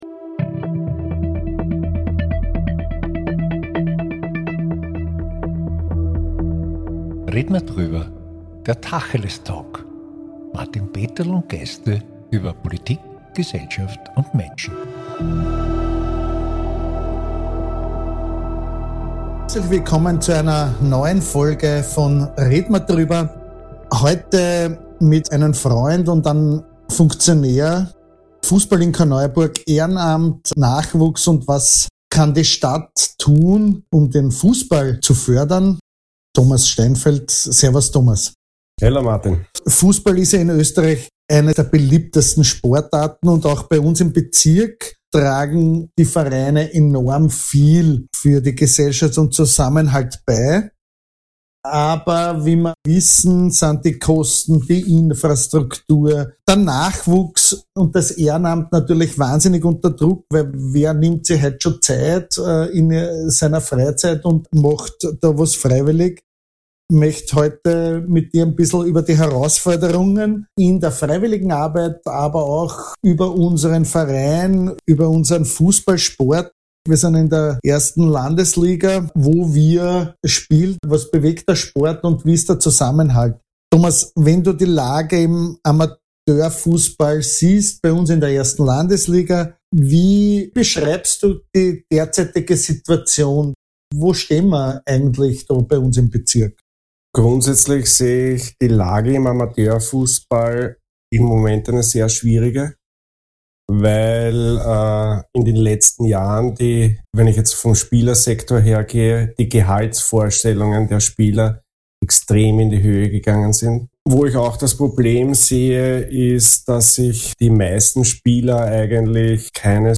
Seit 100 Jahren dreht sich hier alles um den Ball. In einem spannenden Gespräch